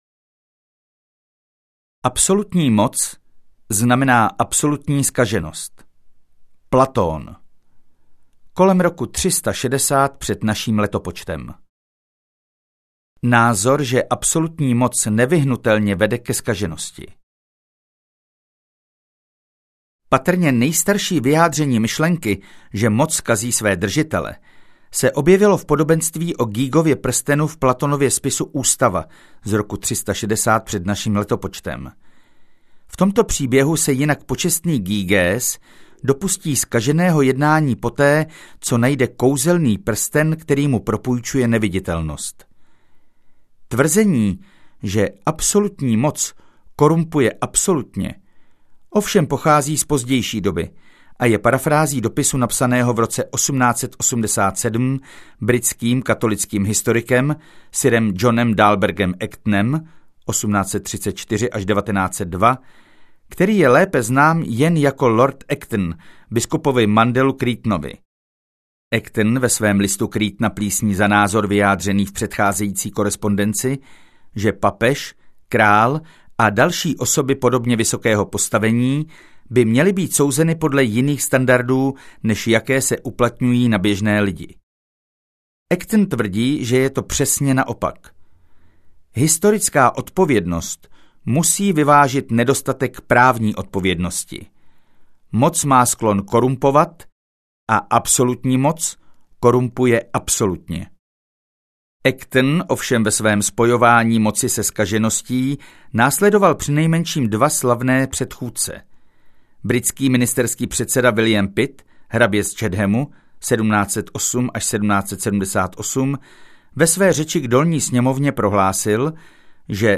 1001 myšlenek: část Filozofie audiokniha
Ukázka z knihy